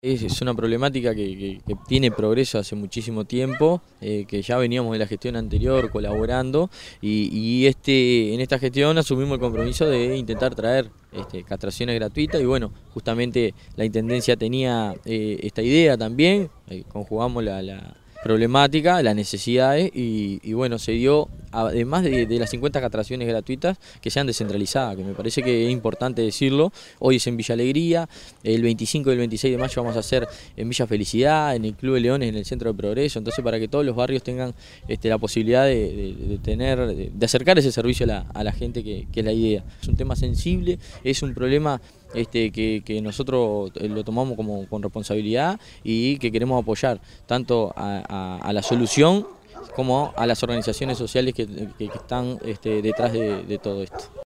Por su parte, el Alcalde del Municipio de Progreso, Claudio Duarte, comentó que “en esta gestión se asumió un compromiso de poder acercar el servicio gratuito de castraciones a vecinas y vecinos, ya que la tenencia responsable es una problemática importante en Progreso”.
claudio_duarte_alcalde_de_progreso.mp3